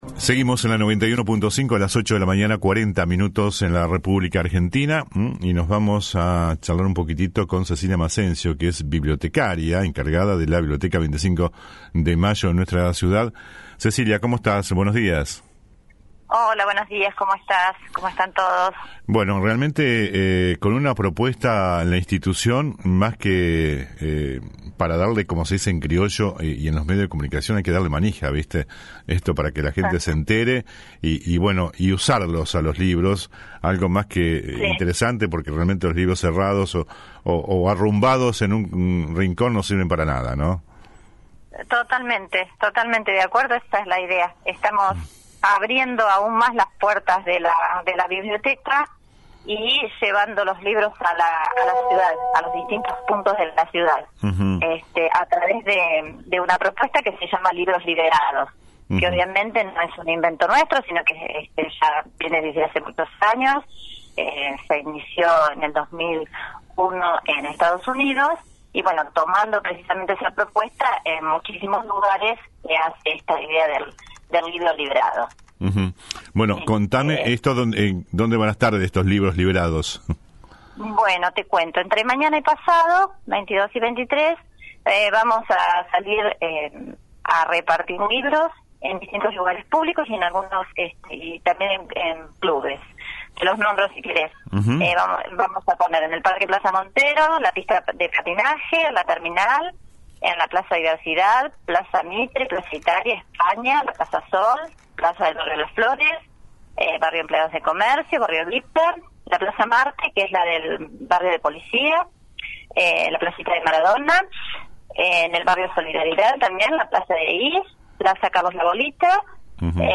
AUDIO DE LA ENTREVISTA COMPLETA